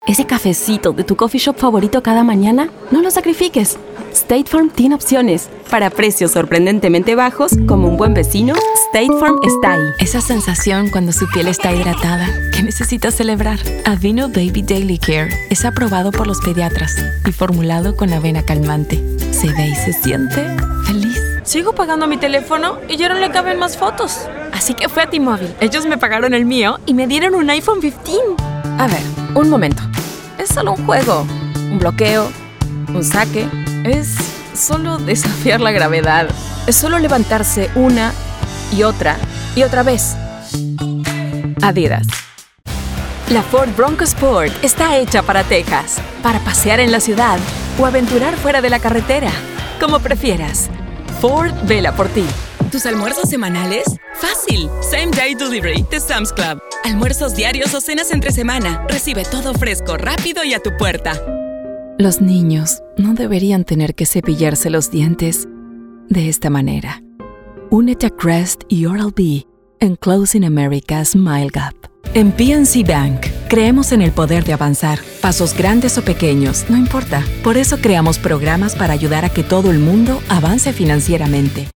Spanish Commercial